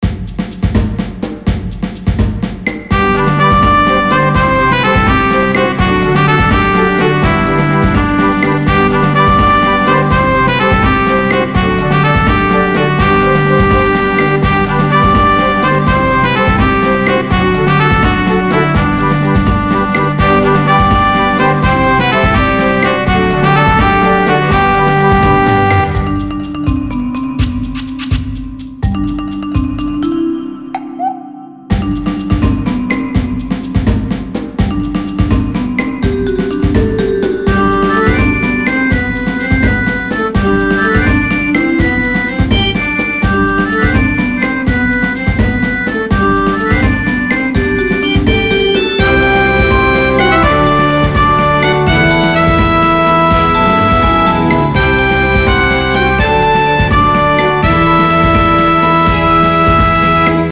【1.15MB　1:01】イメージ：元気、トランペット　※ループ素材